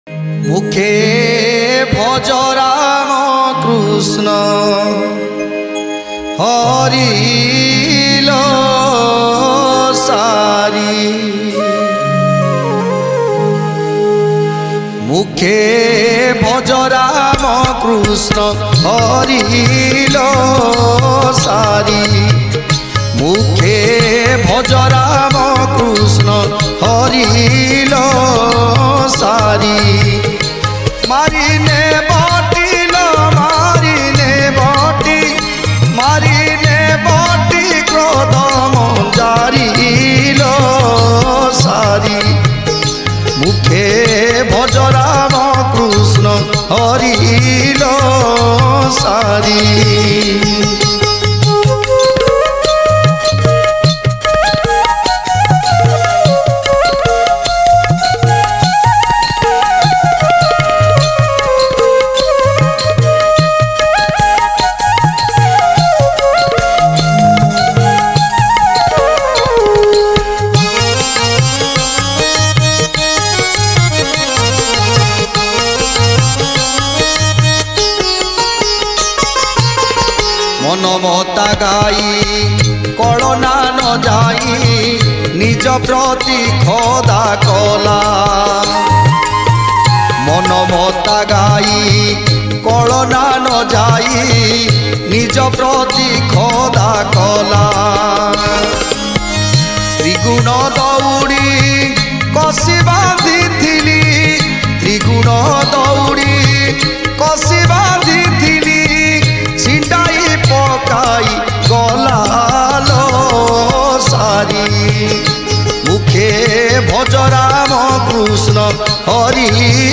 Odia Bhajan Song